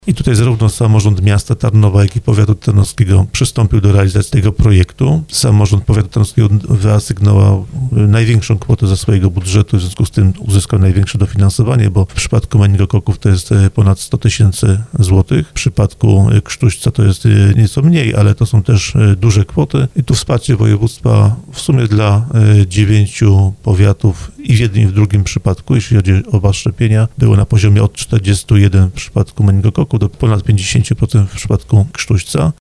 Mówił o tym w audycji Słowo za Słowo wiceprzewodniczący Sejmiku Wojciech Skruch.